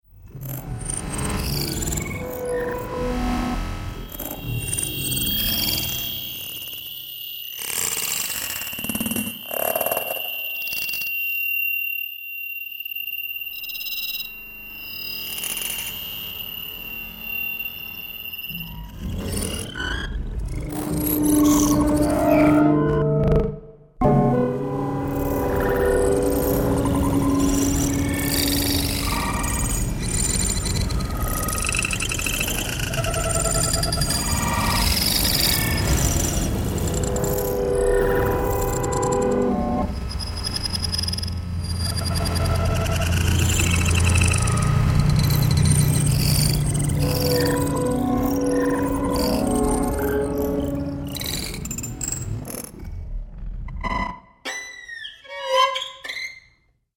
violins